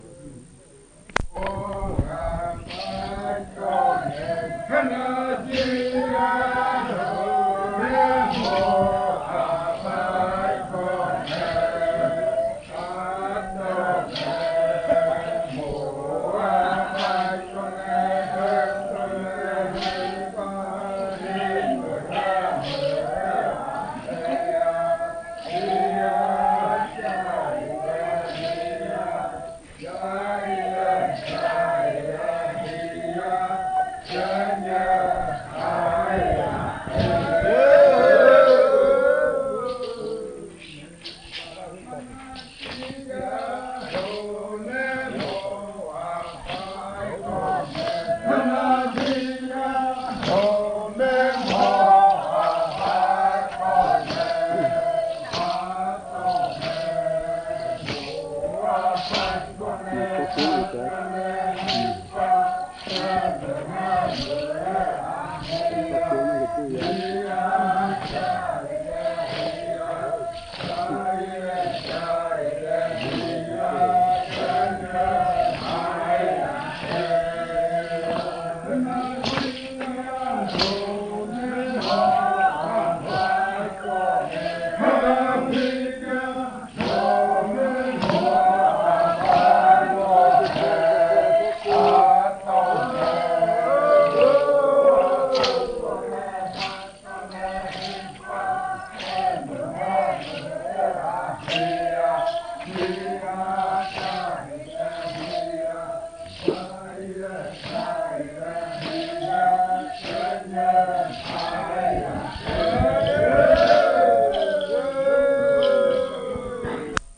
23. Baile de nombramiento. Canto n°25
Puerto Remanso del Tigre, departamento de Amazonas, Colombia